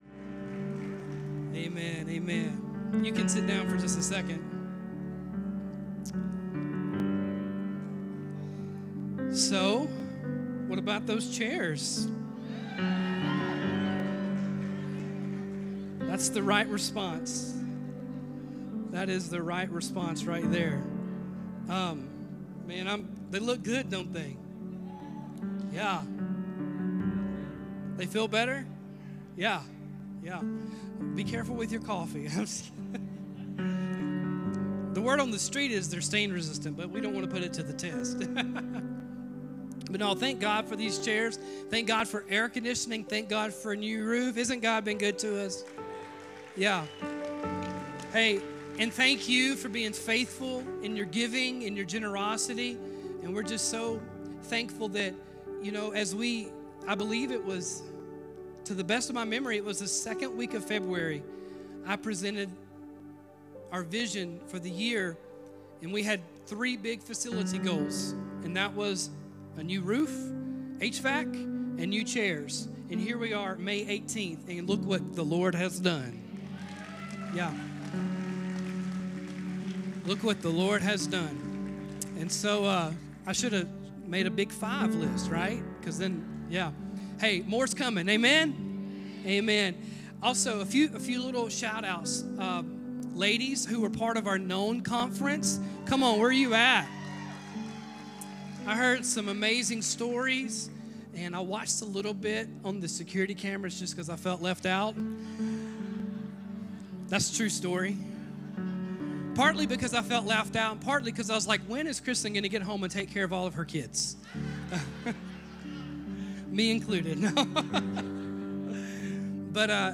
This week's sermon is "The Perils of Passivity". Taken from the story of David and Bathsheba, we learn more that passivity leads to vulnerability, sin thrives in the dark, repentance is the pathway to restoration, and God is a redeemer.